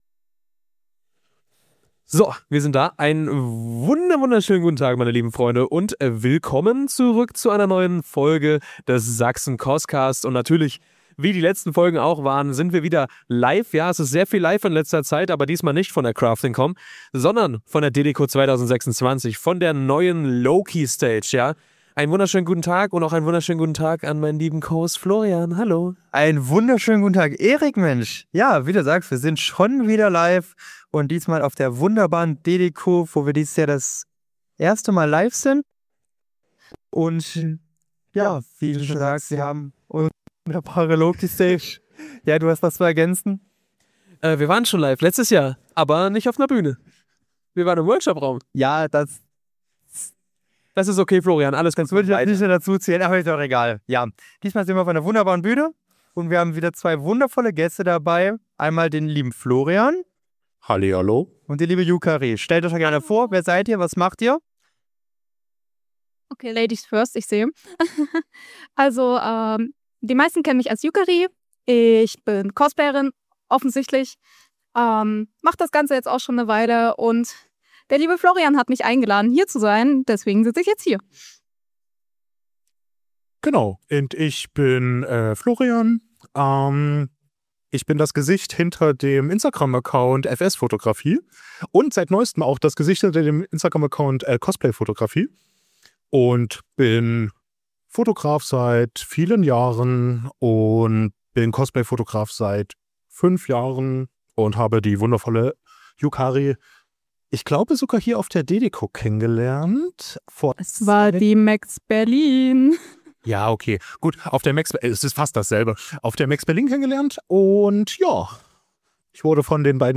Folge 19 | Live | Live von der DeDeCo 2026 ~ Sachsen-CosCast Podcast
Beschreibung vor 6 Tagen Auch in 2026 hatten wir wieder die Chance eine Live Episode des Sachsen-CosCast auf der DeDeCo zu veranstalten, nur diesmal auf der neuen Loki Stage.